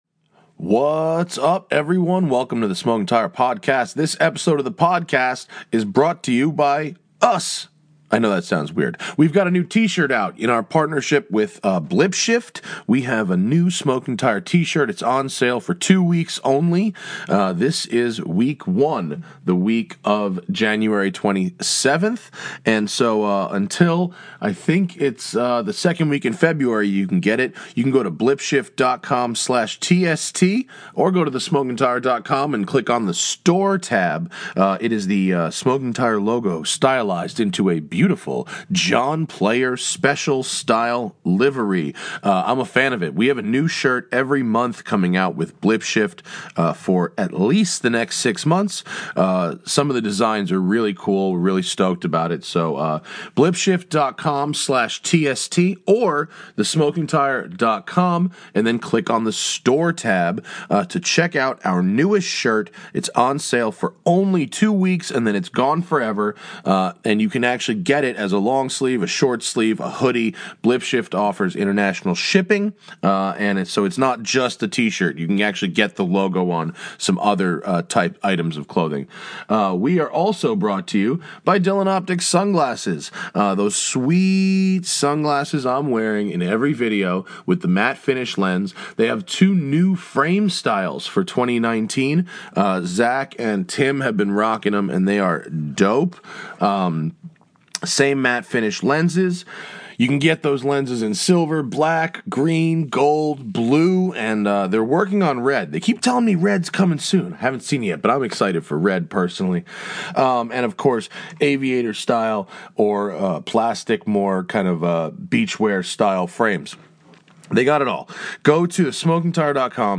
This audio comes from a backup recording that was recorded on an iPhone, and started 15 minutes in. We apologize for the audio quality.